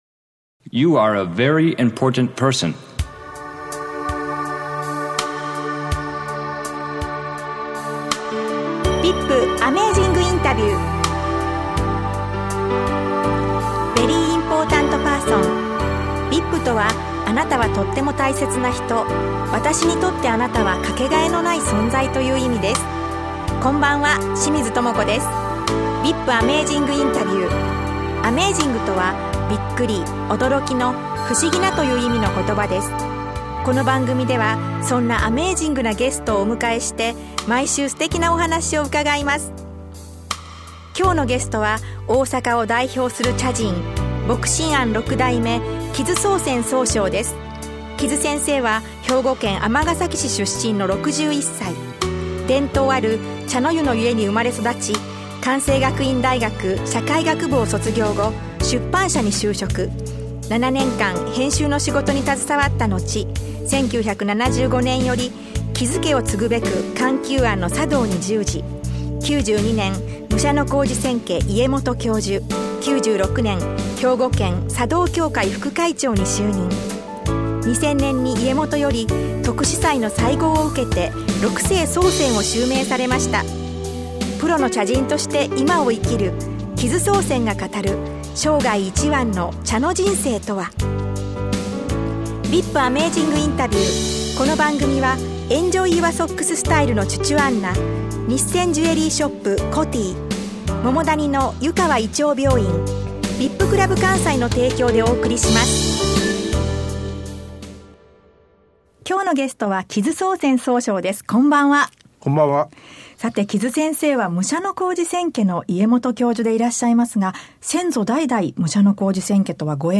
VIPアメージングインタビュー
インターネット放送をお聴き頂けます。（ラジオ放送とは一部内容が異なります）